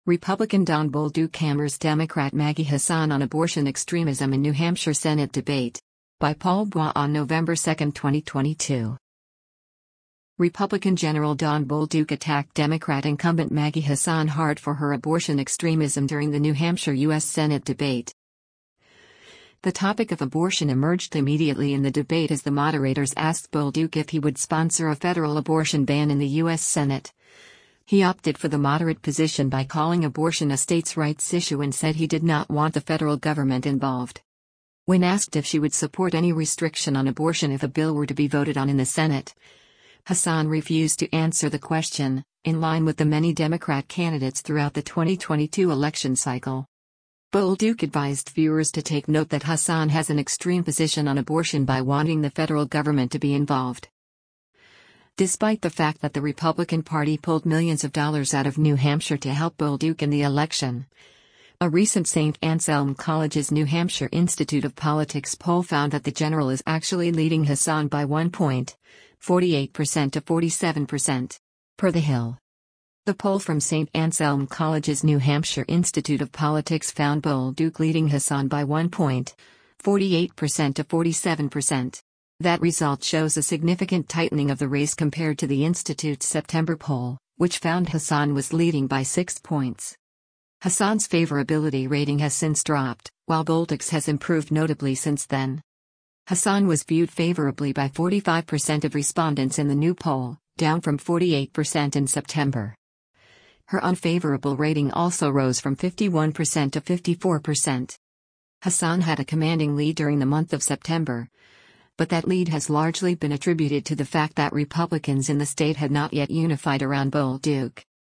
Republican General Don Bolduc attacked Democrat incumbent Maggie Hassan hard for her abortion extremism during the New Hampshire U.S. Senate debate.